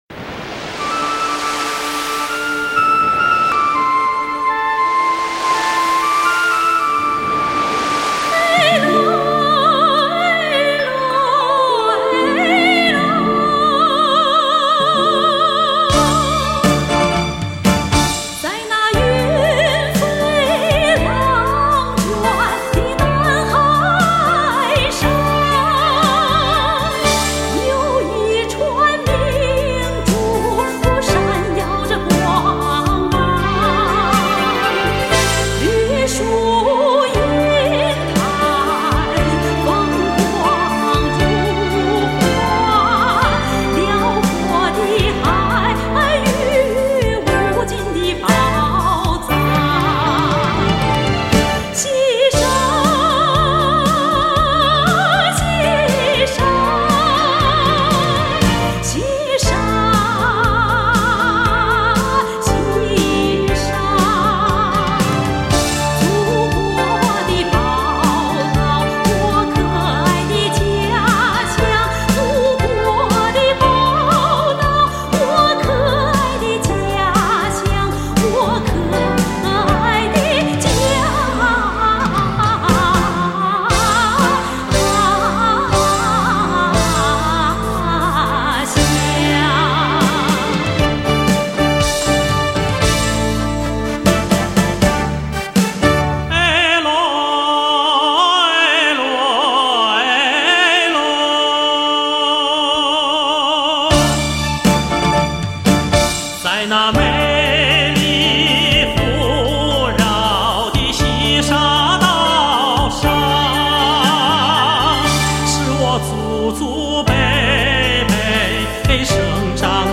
音质：正版CD转320K/MP3